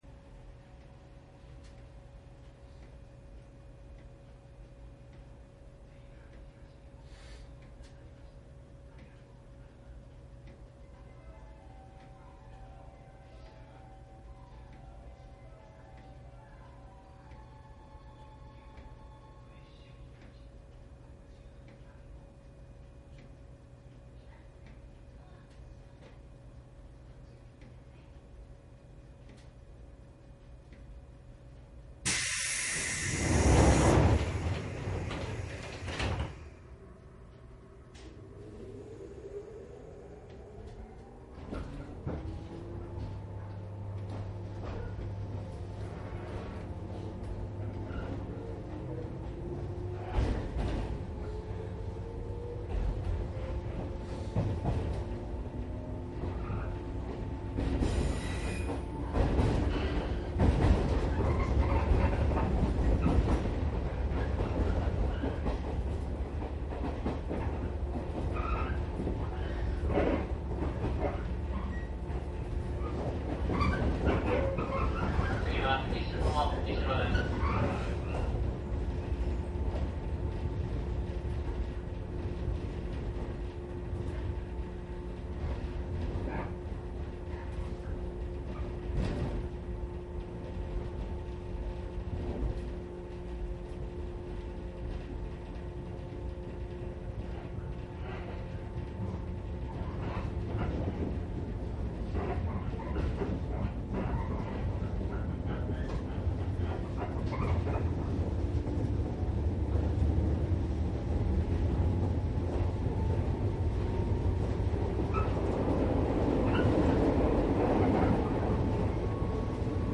常磐線415系上り走行音CD
JR常磐線415系上り 上野まで録音しました。
どちらも鋼製車ですがモーターの響きが微妙に違います。
サンプル音声 モハ４１５-５０７.mp3
いずれもマイクECM959です。TCD100の通常SPモードで録音。
実際に乗客が居る車内で録音しています。貸切ではありませんので乗客の会話やが全くないわけではありません。